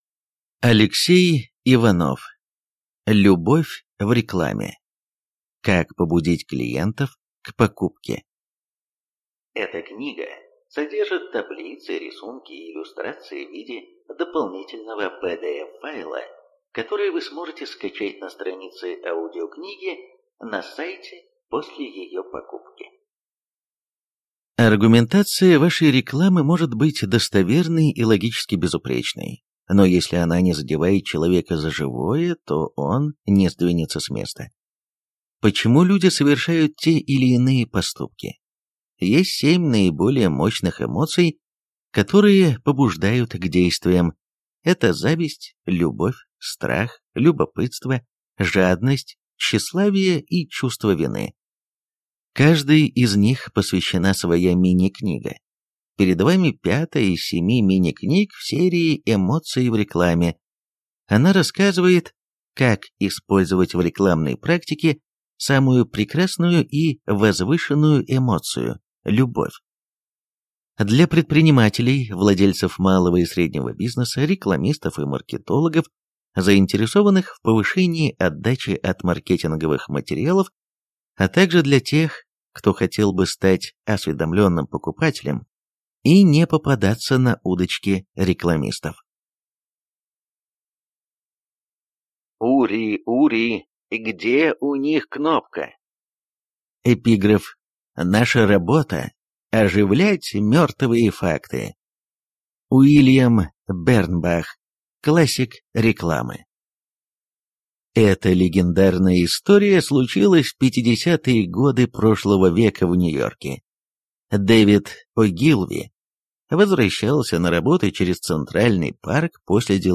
Аудиокнига Любовь в рекламе. Как побудить клиентов к покупке | Библиотека аудиокниг